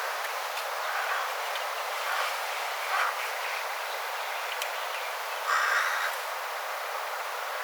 variksen poikanen matkii telkän ääntä?,
variksen poikasen yksi ääni lopussa
ilmeisesti_variksen_poikanen_matkii_telkan_aantelya_variksen_poikanen.mp3